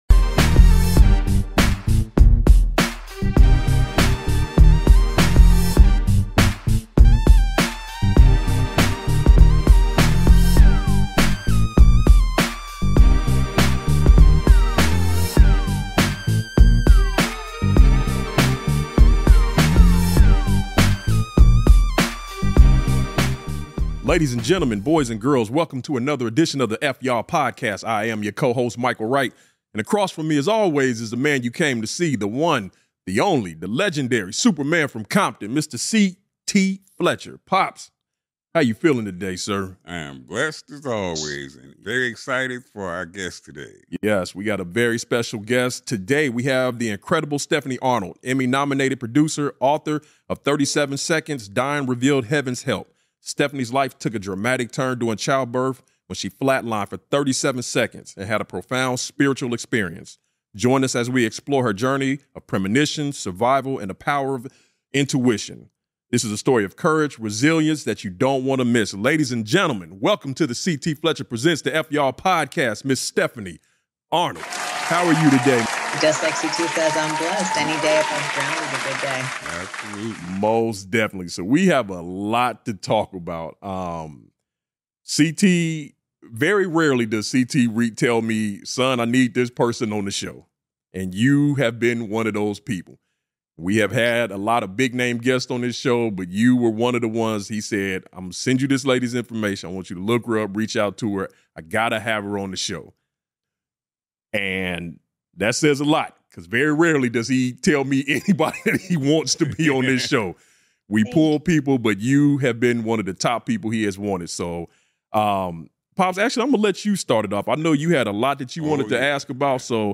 Join us for an inspiring and deeply moving interview